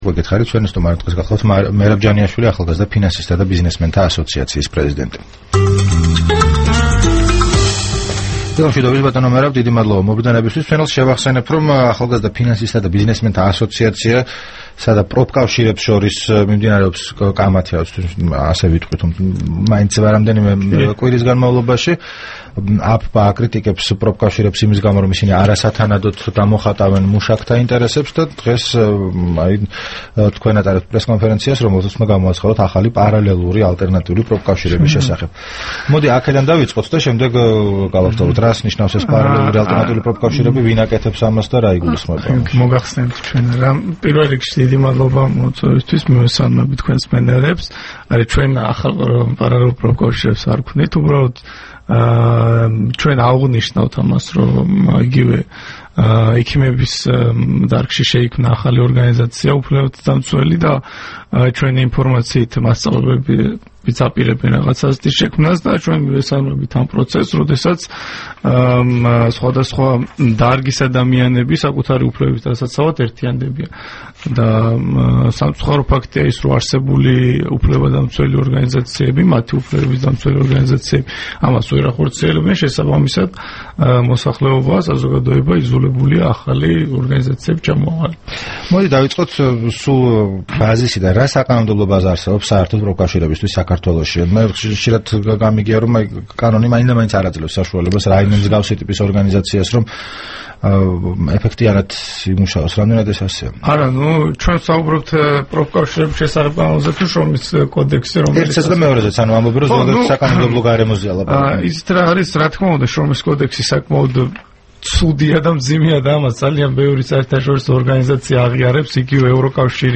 რადიო თავისუფლების თბილისის სტუდიაში სტუმრად იყო
საუბარი